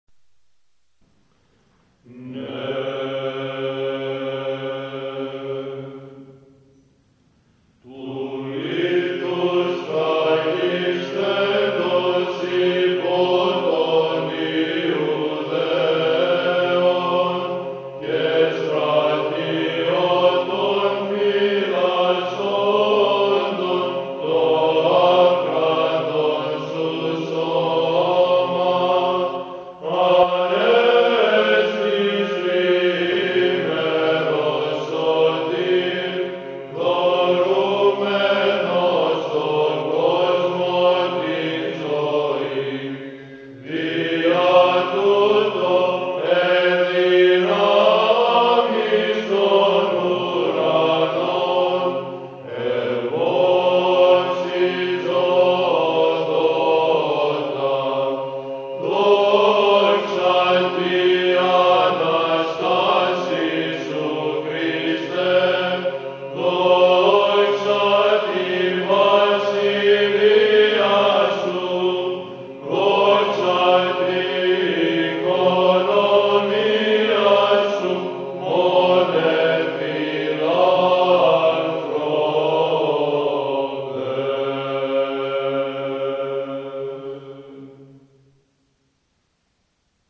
Apolitikion da Ressurreição (Modo 1º)
mode1_greek.mp3